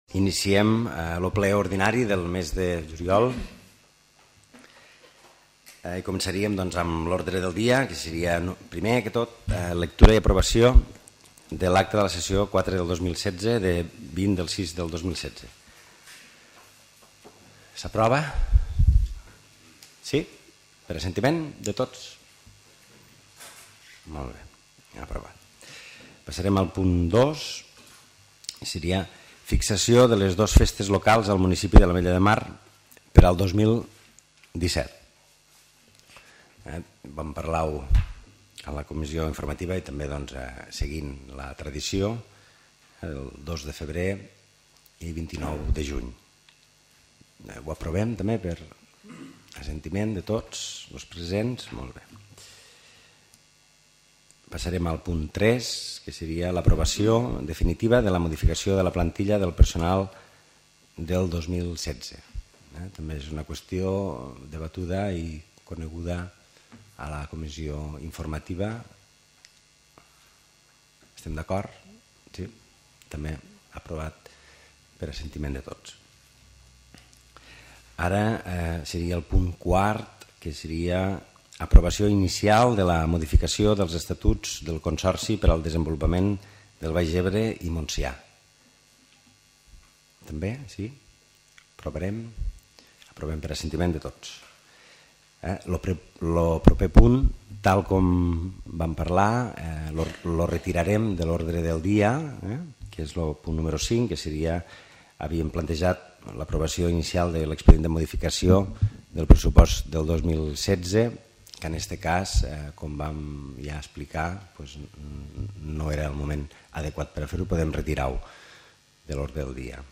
Àudio del Ple del divendres dia 29 de juliol de 2016.